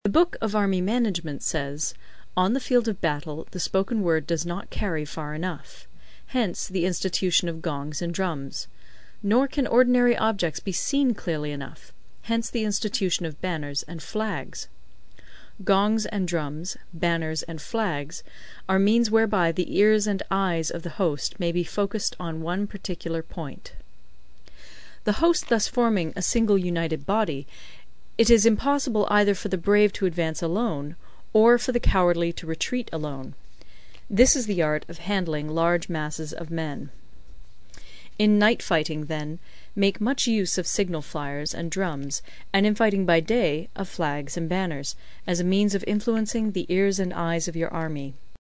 有声读物《孙子兵法》第42期:第七章 军争(4) 听力文件下载—在线英语听力室